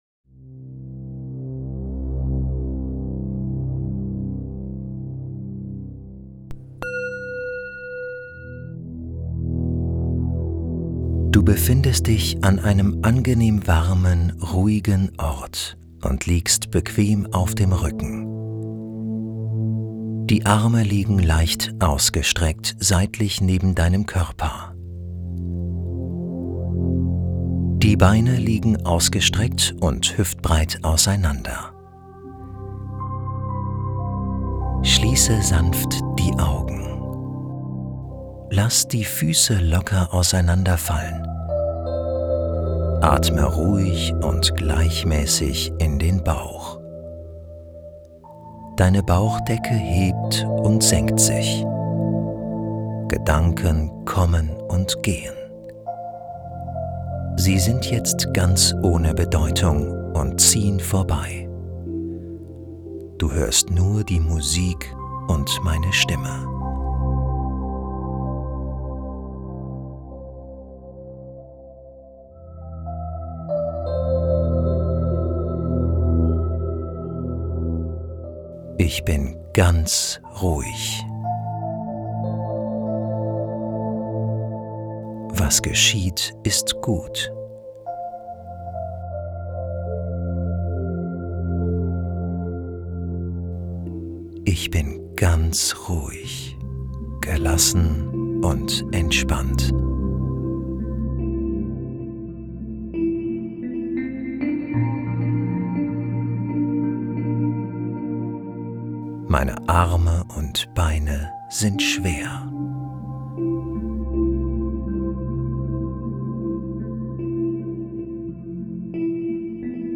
Hörbuch 1